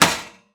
metal_impact_light_02.wav